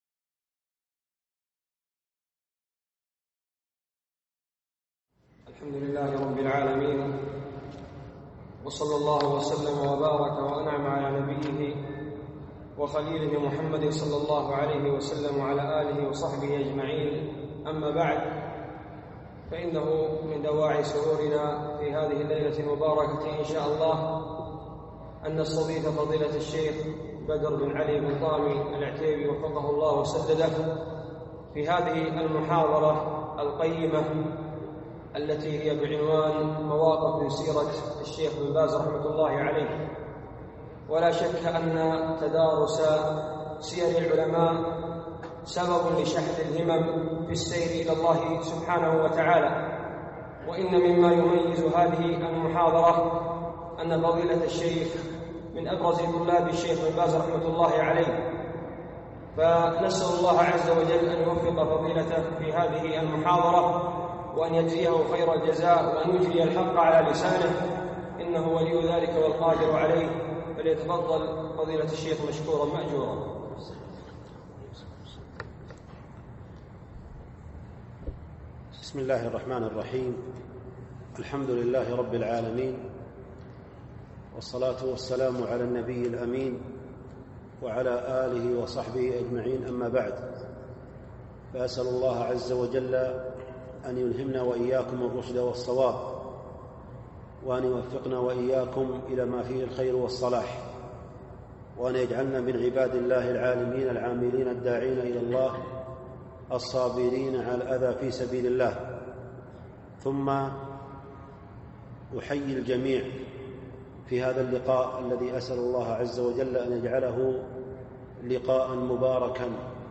أقيمت المحاضرة في جامع الأميرة العنود مغرب الخميس 6 2 1439 في مدينة الدمام